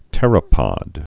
(tĕrə-pŏd)